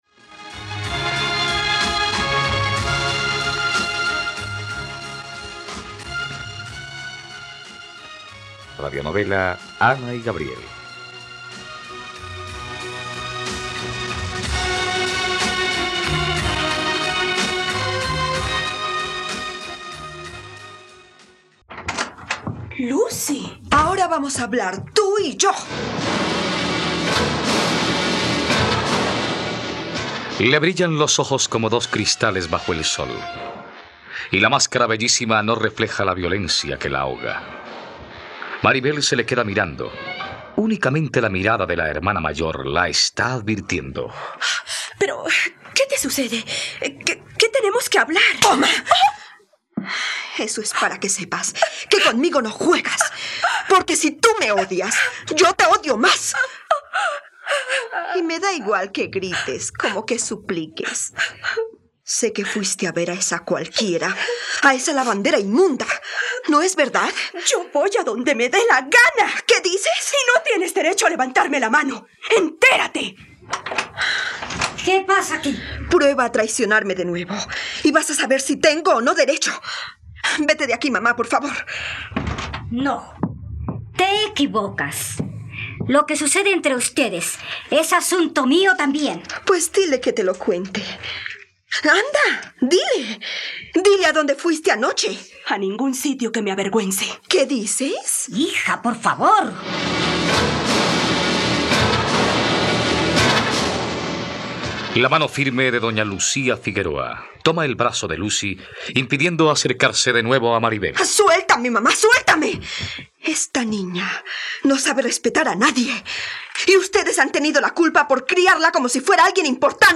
Ana y Gabriel - Radionovela, capítulo 66 | RTVCPlay